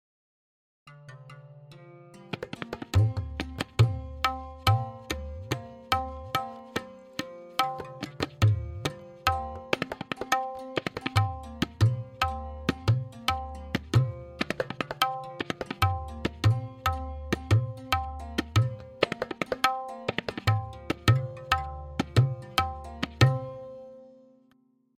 Simple Chakradar Variation 3 with Theka and Lahra
M4.2-SimpleCK-V3-Theka-Lehra.mp3